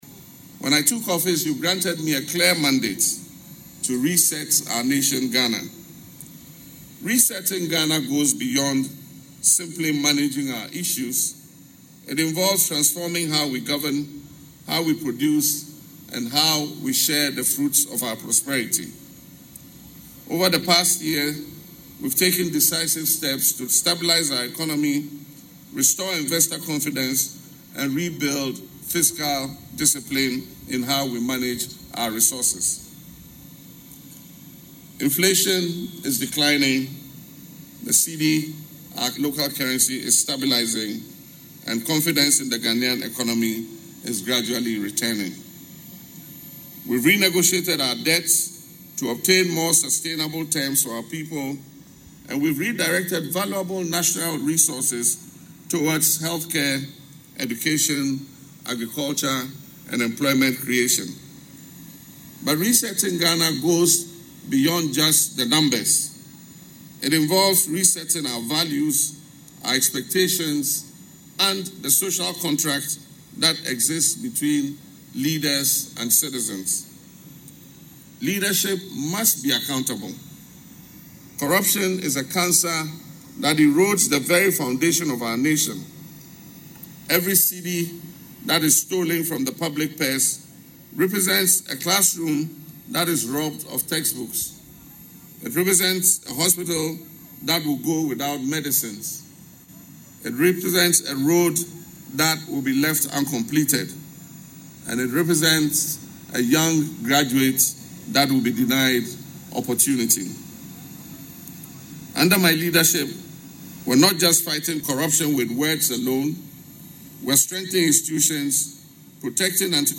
Delivering his address at Ghana’s Independence Day celebration marking 69 years of independence, the President warned that corruption continues to rob citizens of vital development opportunities while betraying the sacrifices of the country’s founding generation.
LISTEN TO PRESIDENT MAHAMA IN THE AUDIO BELOW: